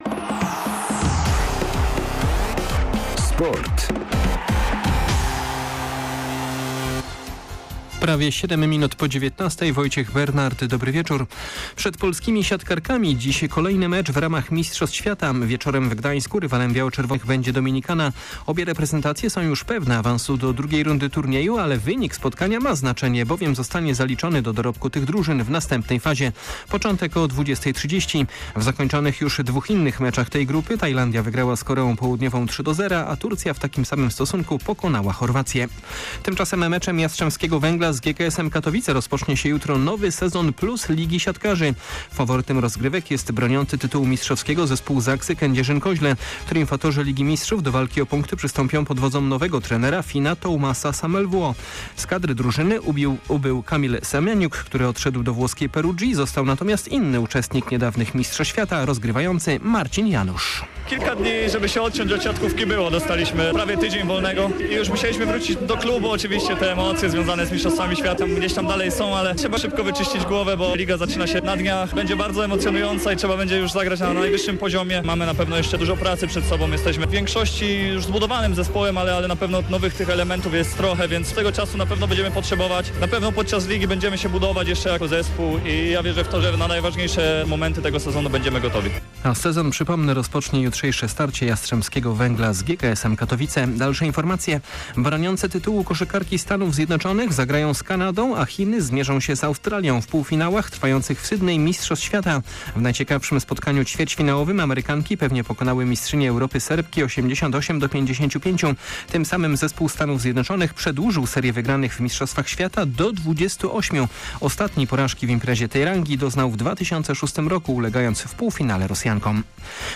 29.09.2022 SERWIS SPORTOWY GODZ. 19:05